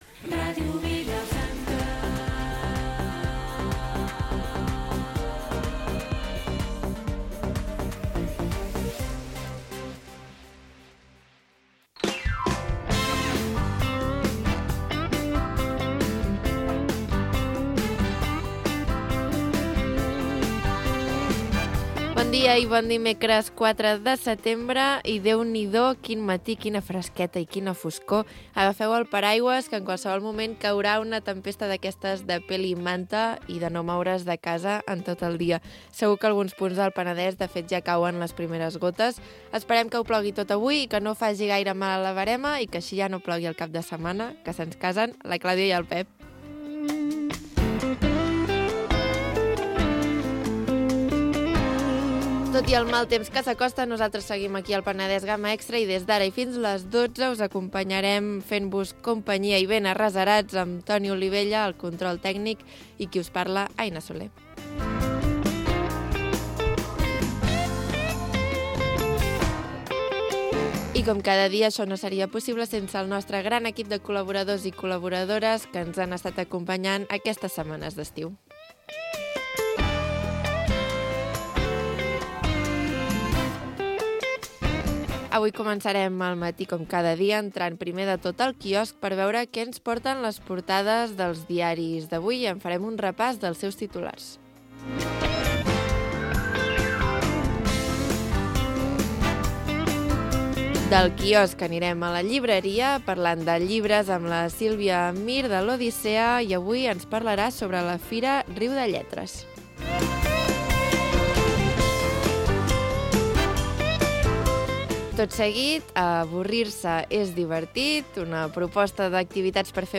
Magazín diari d'estiu